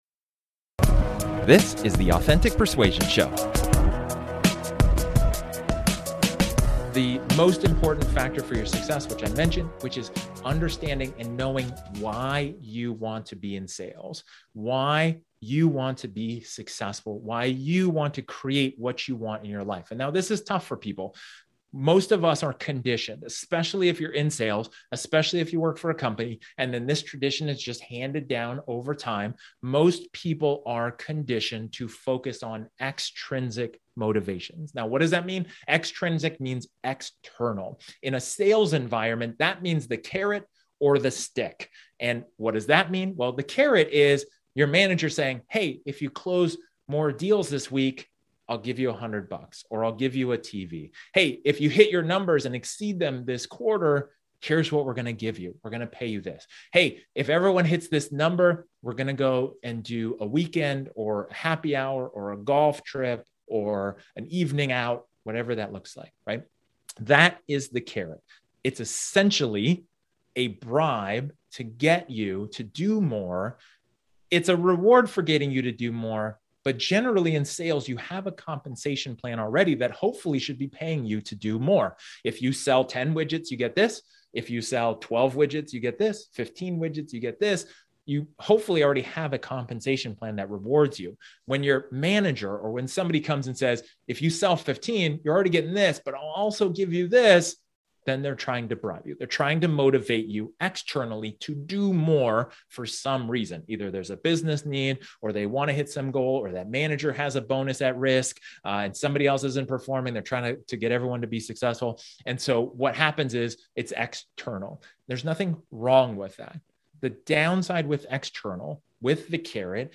In this solo episode, I talk about how extrinsic motivation works, especially the good side and the downside of it.